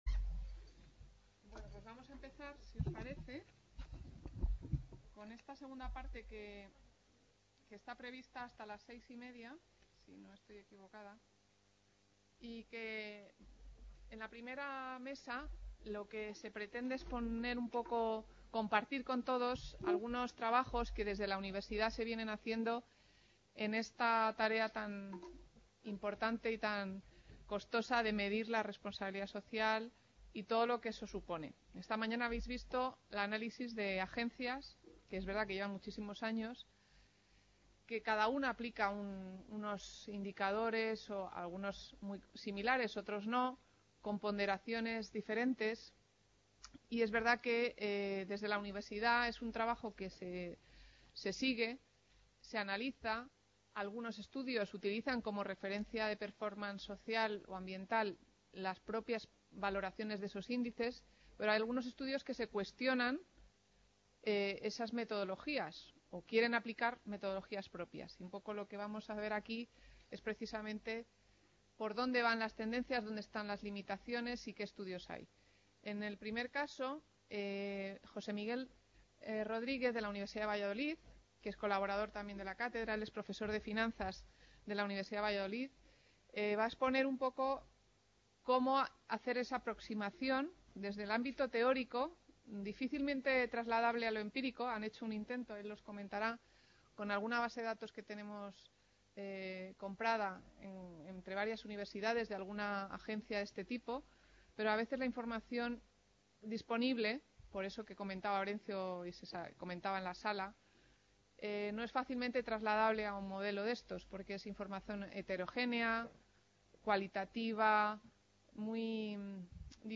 Taller sobre metodologías, limitaciones, oportunidades sobre medición de la RSC
Reunion, debate, coloquio...